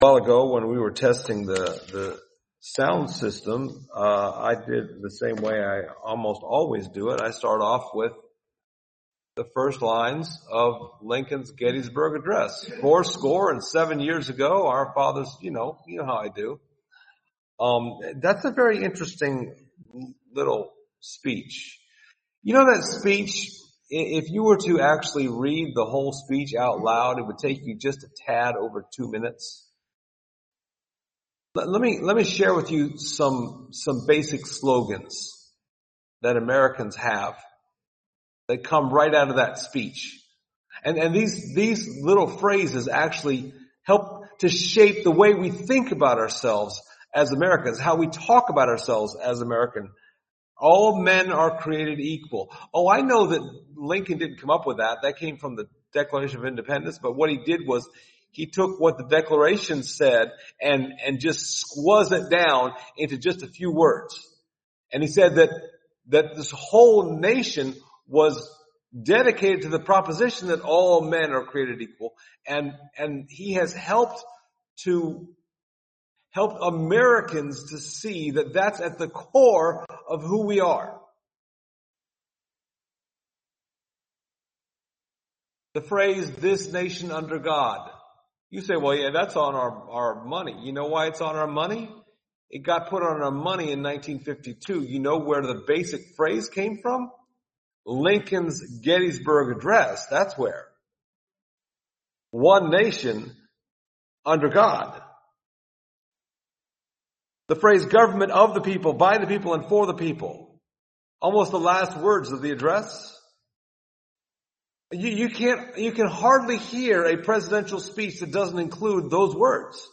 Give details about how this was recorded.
Passage: Psalm 110 Service Type: Sunday Morning